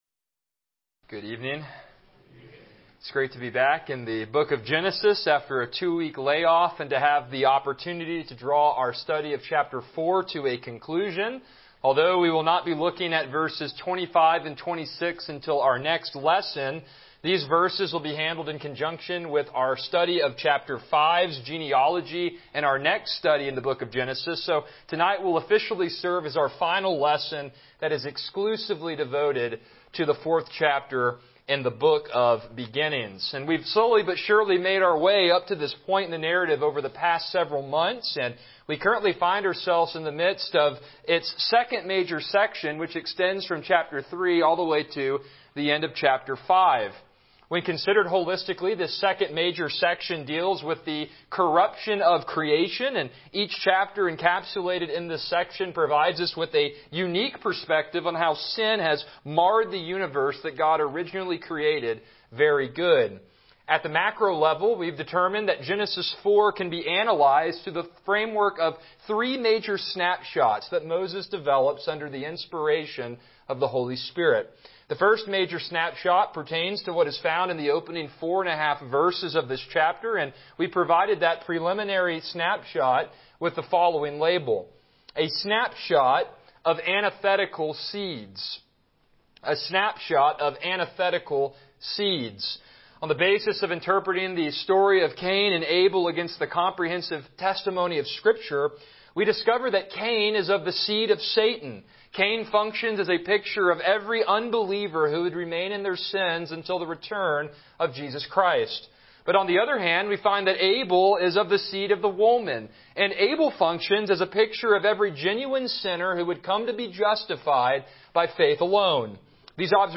Passage: Genesis 4:13-24 Service Type: Evening Worship